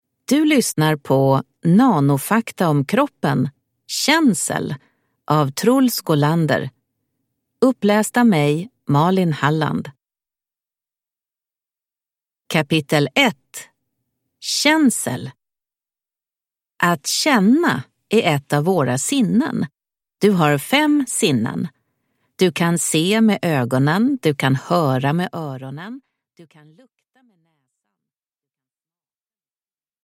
Nanofakta om kroppen. Känsel – Ljudbok